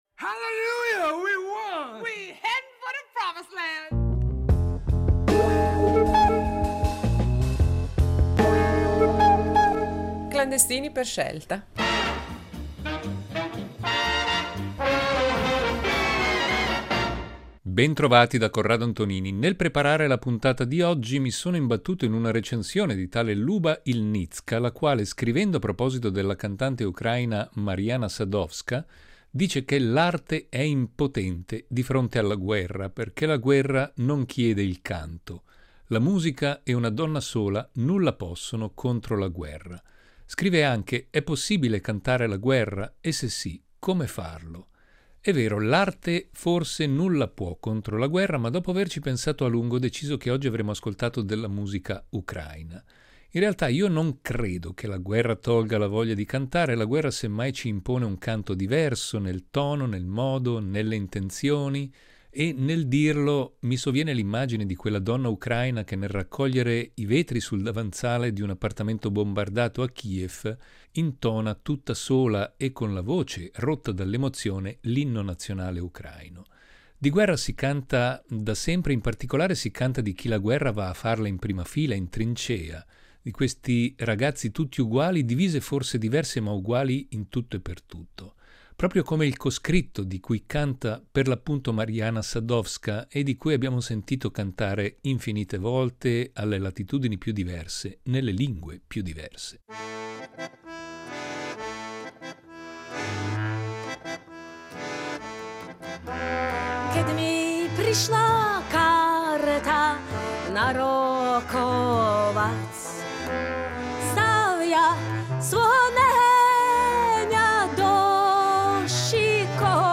Sono solo alcuni dei nomi della nuova scena musicale ucraina che si muove fra la tradizione folklorica e i più diversi influssi della modernità.